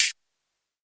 UHH_ElectroHatA_Hit-03.wav